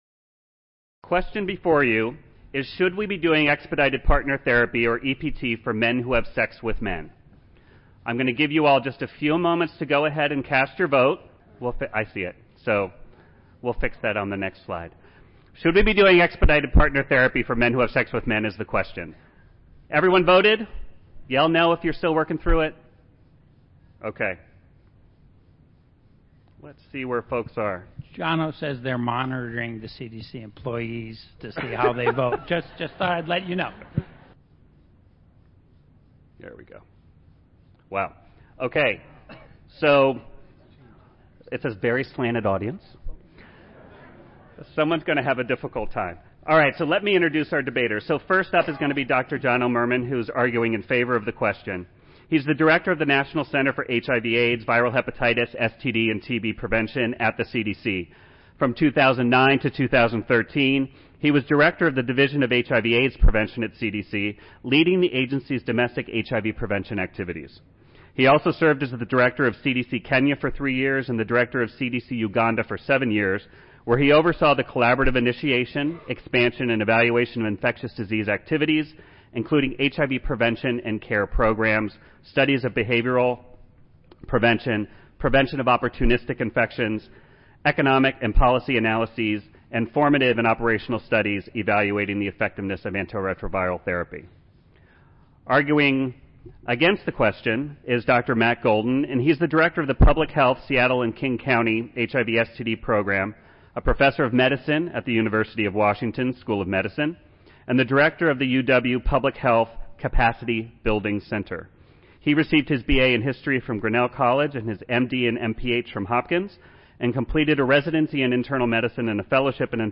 Grand Ballroom